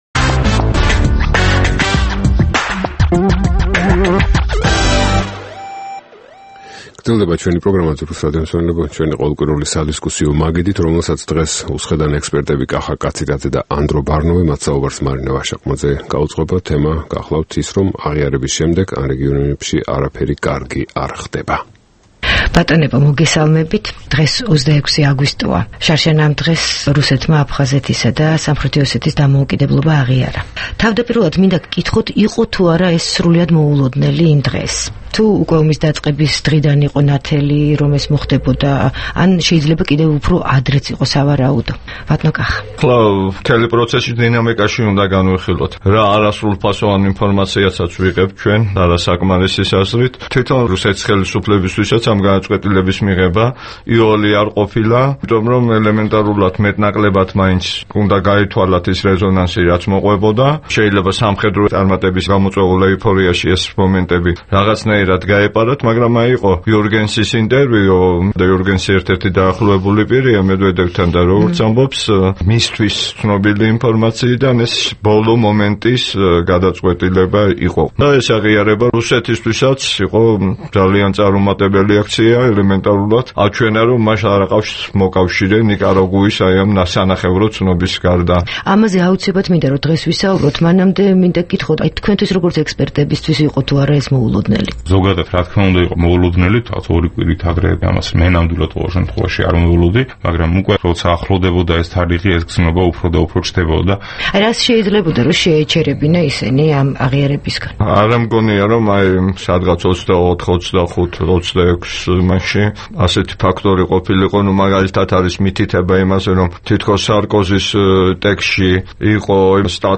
რადიო თავისუფლების დღევანდელ სადისკუსიო მაგიდასთან